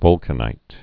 (vŭlkə-nīt)